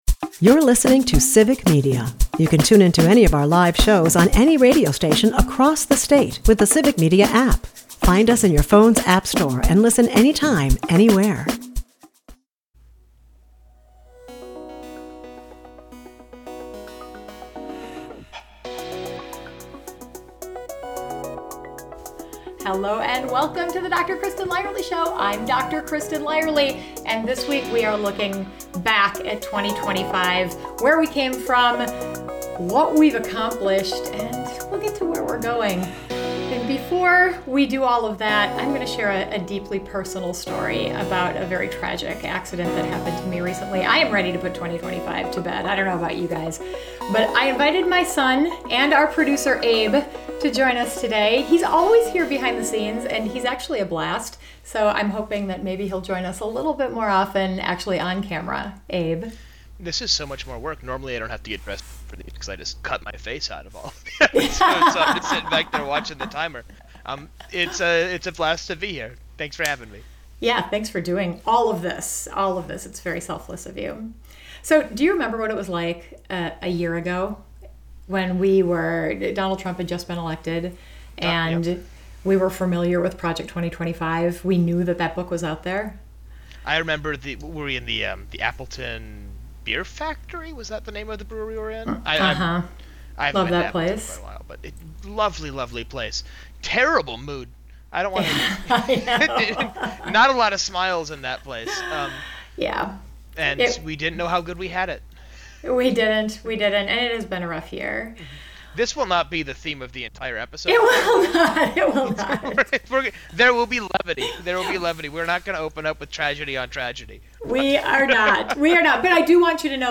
These aren't just interviews, these are the conversations that shaped the year. From the fight for healthcare to the future of Wisconsin, from tariffs to protecting our voting rights, we've covered it all.